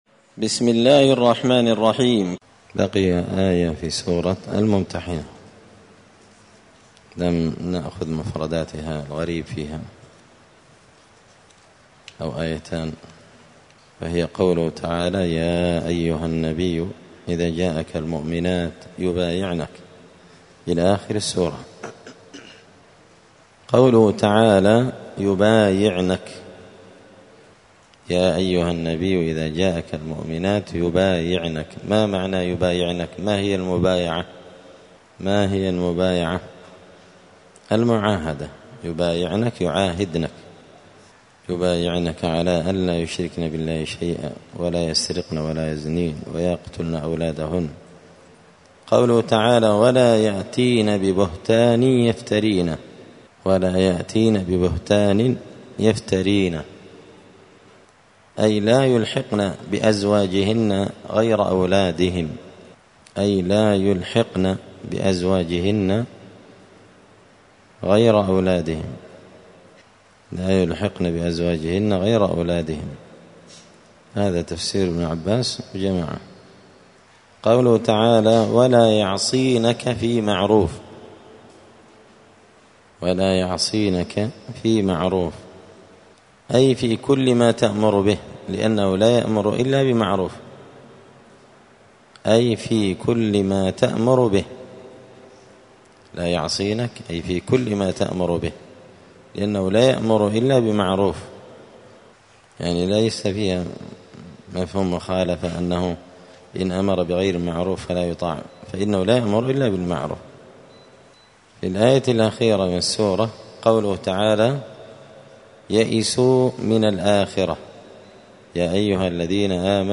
زبدة الأقوال في غريب كلام المتعال الدرس الثامن والثلاثون بعد المائة (138)
دار الحديث السلفية بمسجد الفرقان بقشن المهرة اليمن